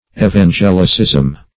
Evangelicism \E`van*gel"i*cism\n.
evangelicism.mp3